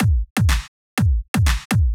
123 BPM Beat Loops Download